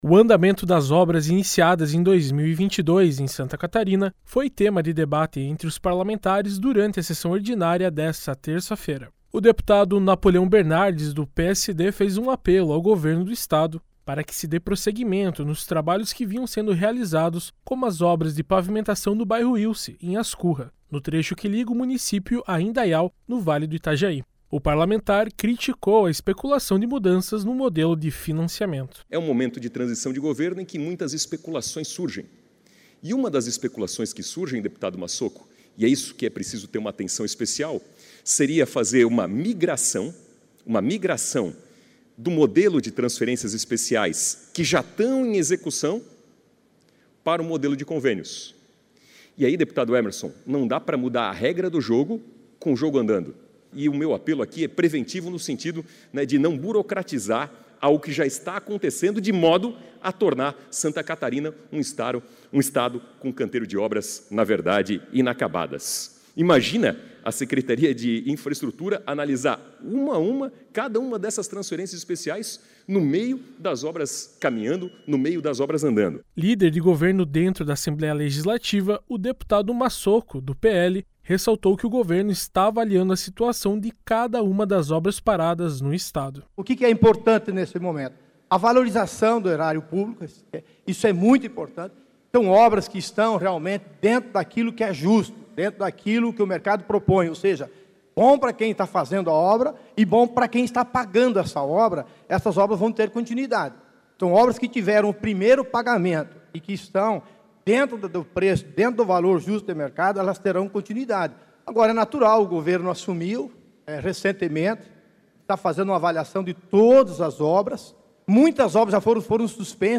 Entrevista com:
- deputado Napoleão Bernardes (PSD);
- deputado Massocco (PL), líder do Governo na Assembleia Legislativa.